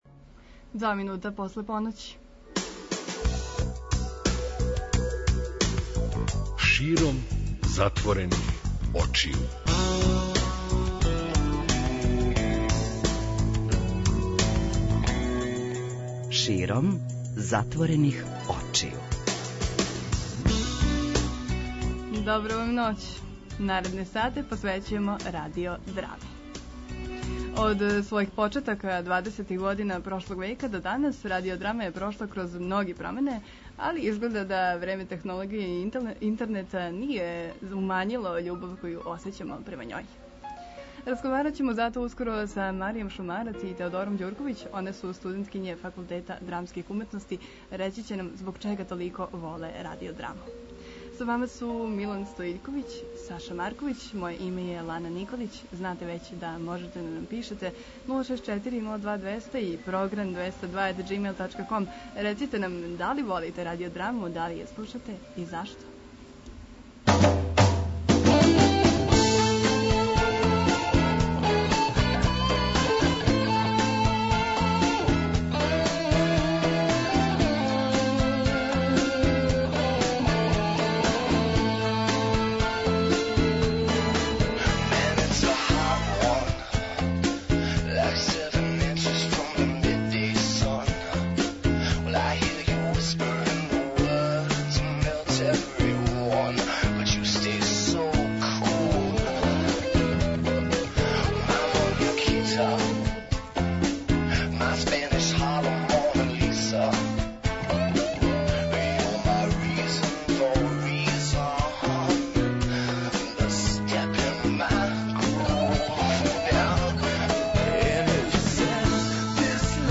Оне су нам ноћас у гостима, заједно слушамо делове награђених радио-драма и разговарамо о будућности ове врсте стварања и љубави младих према њој.